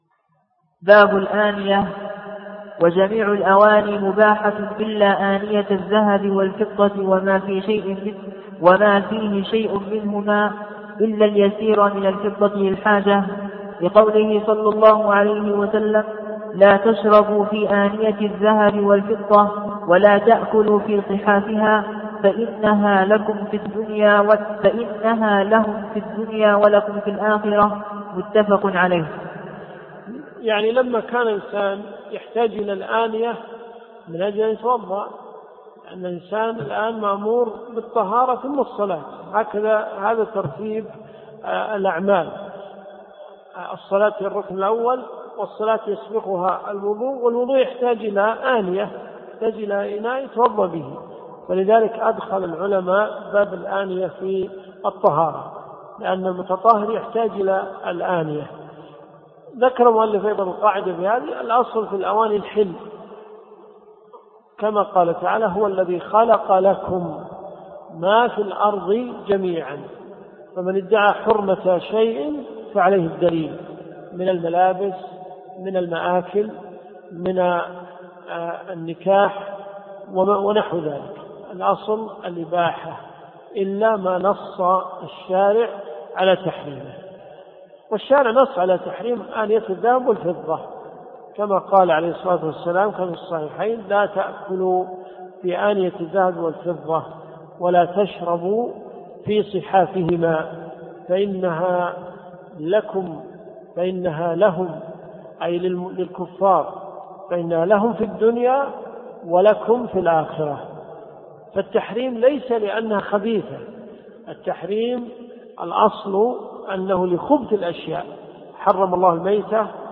الدروس الشرعية
مقطع مأخوذ من شرح آخر ليجبر السقط .